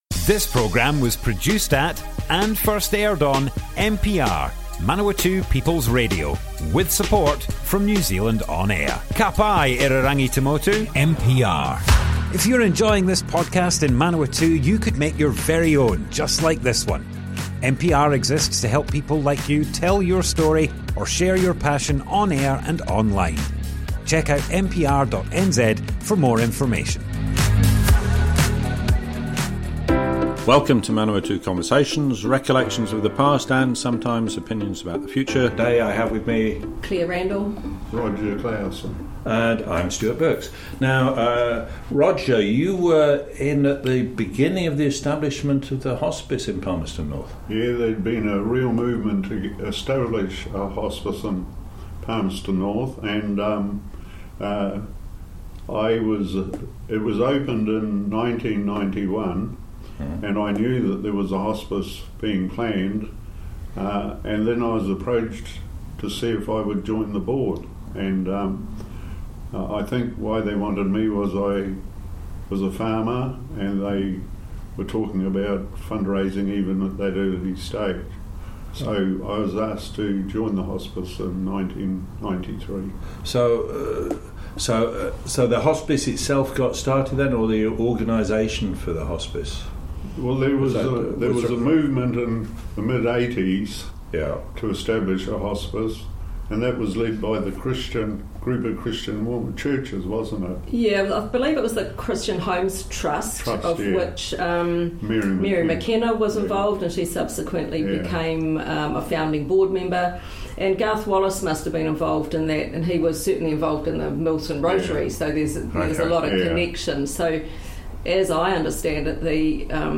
00:00 of 00:00 Add to a set Other Sets Description Comments Arohanui Hospice Part 1 - Manawatu Conversations More Info → Description Broadcast on Manawatu People's Radio, 14th May 2024. Part 1 of 2 A movement was started in mid 1980s to establish a hospice in Palmerston North.
oral history